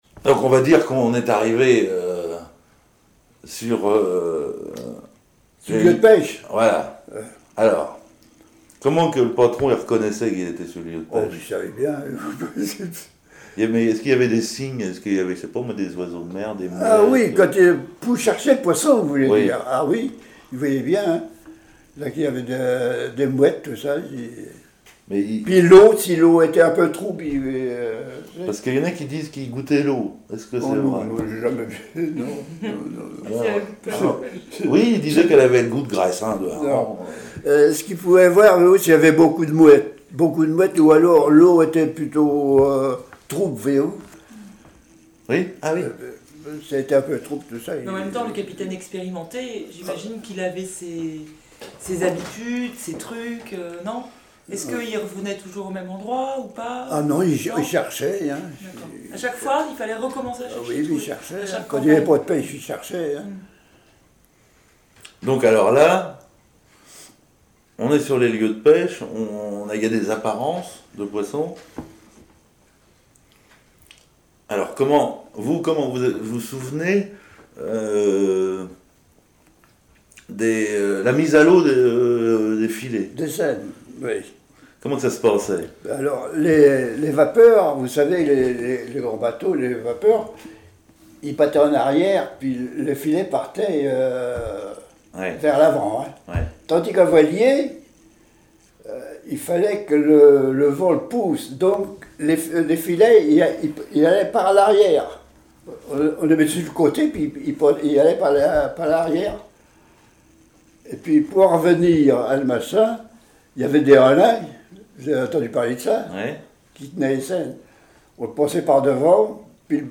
Témoignages sur la pêche sur les voiliers
Catégorie Témoignage